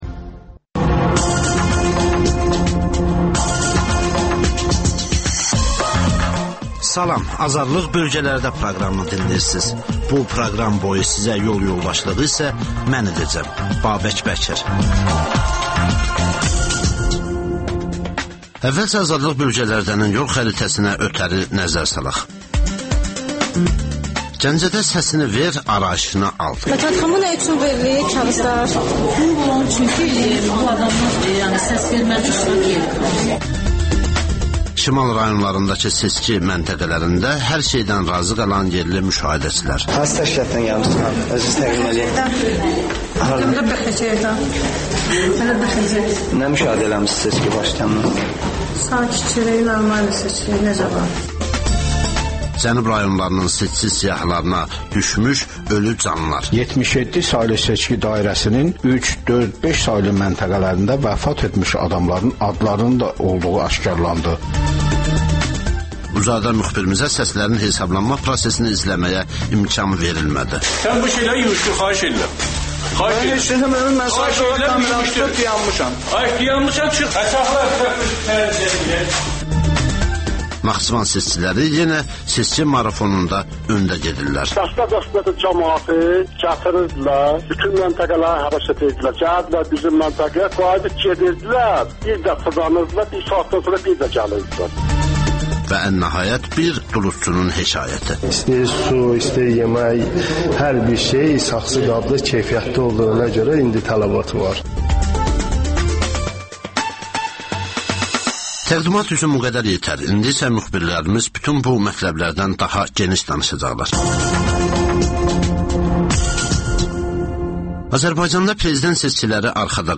Rayonlardan xüsusi reportajlar